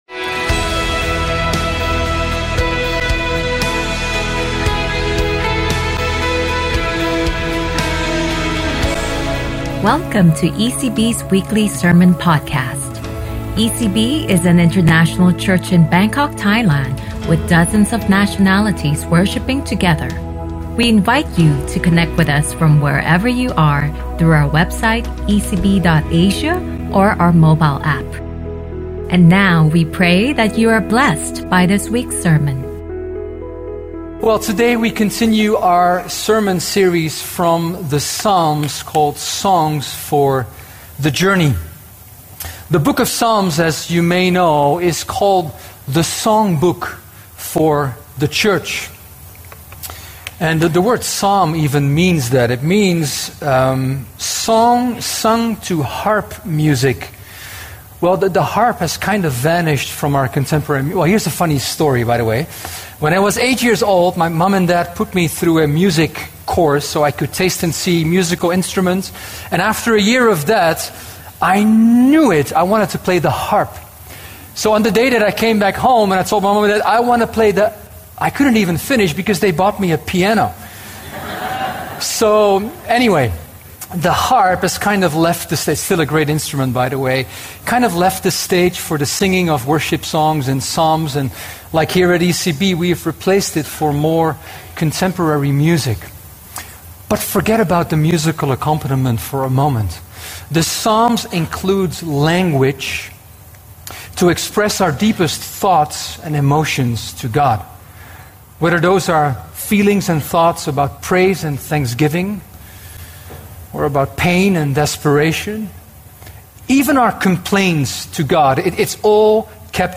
ECB Sermon Podcast